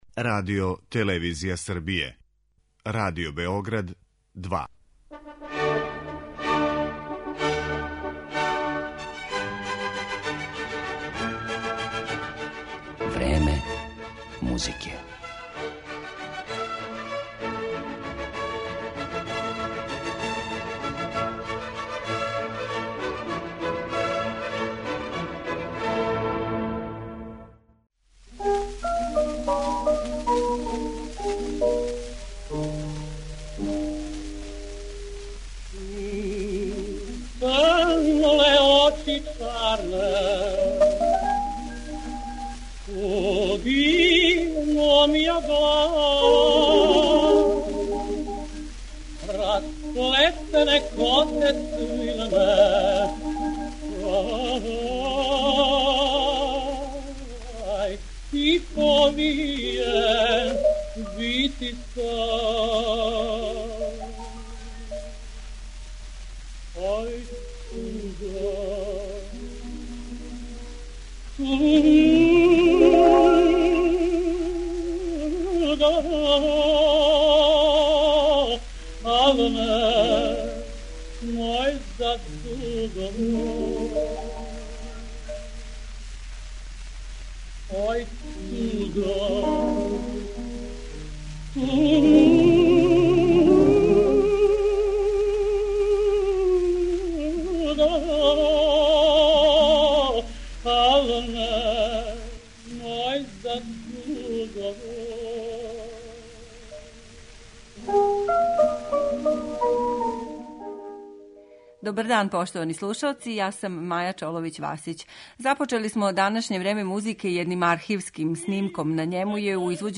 Његов опус ћемо, осим поменутим делима, представити и хорским и оркестарским композицијама, као и соло песмама.